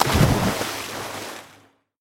feat: water sounds instead of bells
splash-big.mp3